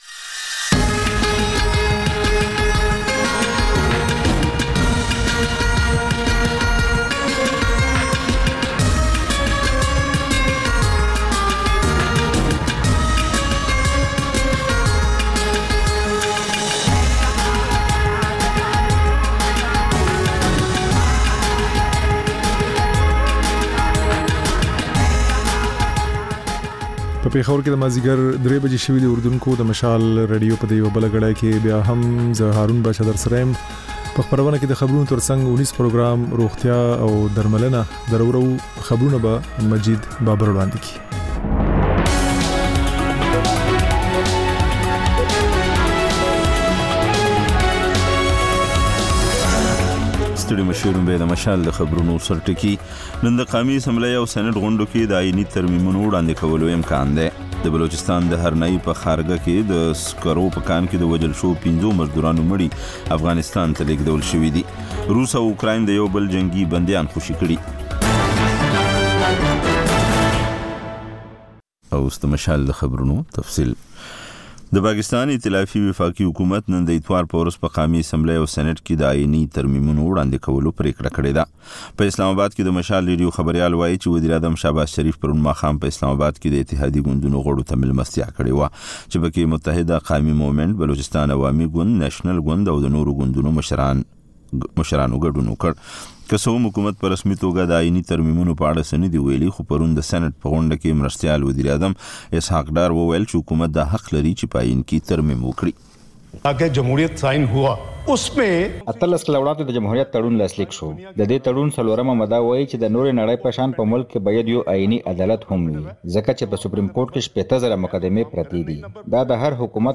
د مشال راډیو درېیمه یو ساعته ماسپښینۍ خپرونه. تر خبرونو وروسته، رپورټونه او شننې خپرېږي.